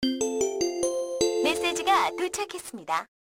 알림음(효과음) + 벨소리
알림음 8_메세지가도착했습니다.ogg